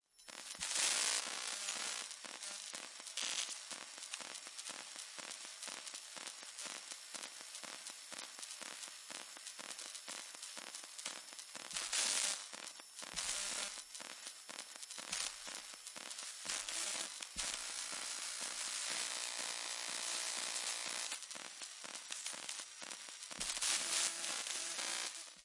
通知声音
描述：一个小的通知声音，我用我的手机。使用Korg Electribe和卡西欧Casiotone 405。
标签： 移动电话 简单 混响 蜂鸣声 通知 电话 手机声 警报 愉快
声道立体声